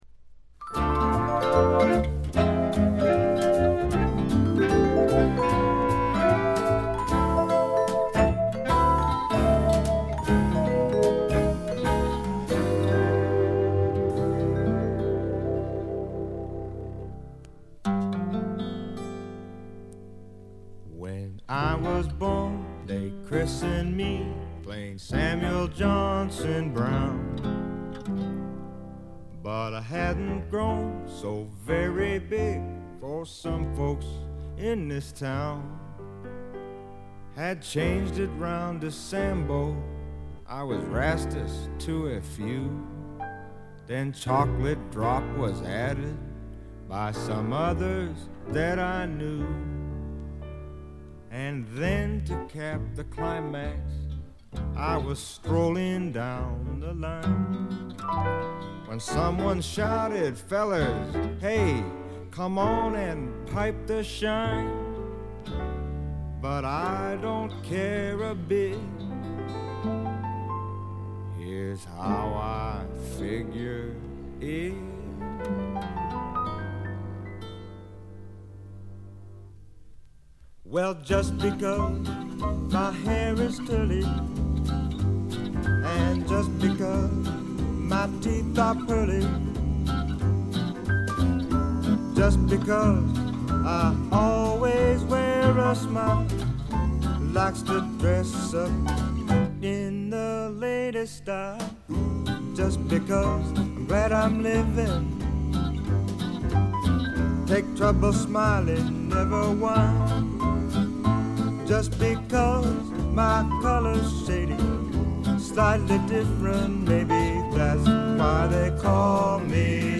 ディスク：部分試聴ですが、静音部での軽微なチリプチ少し。
試聴曲は現品からの取り込み音源です。